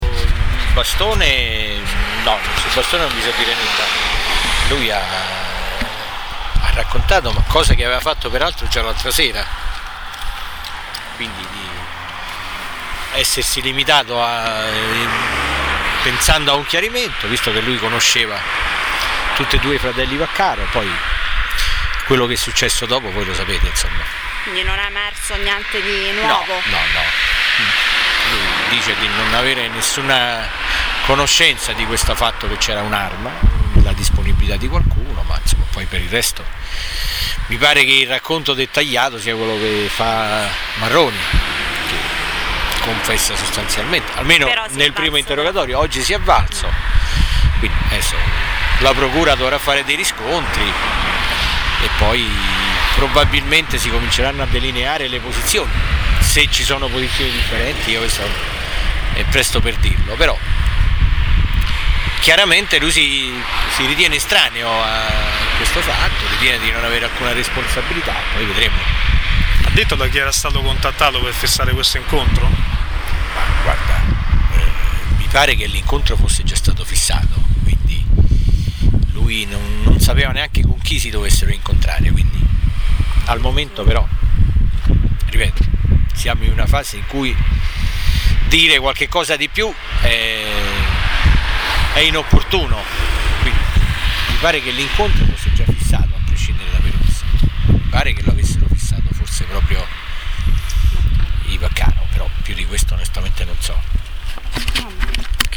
Per i dettagli sulle dichiarazioni ascoltare le interviste agli avvocati difensori rilasciate subito dopo l’interrogatorio:
L’intervista